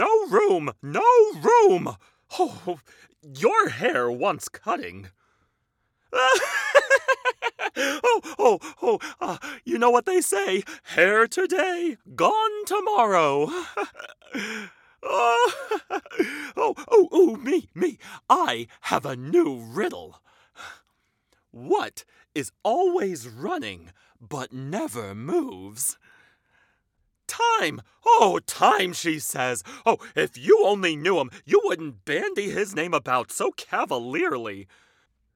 Inglés (Estados Unidos)
Conversacional
Amistoso
Mentecato